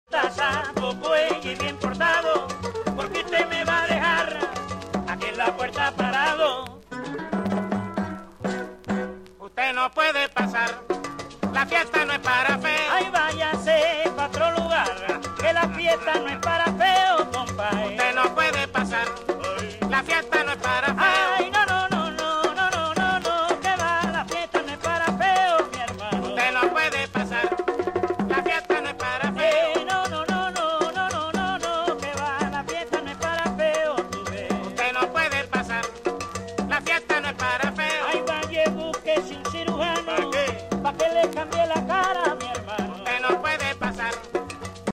Género: Latin, Folk, World, & Country
Estilo: Guaguancó, Cubano, Bolero, Son, Guajira, Guaracha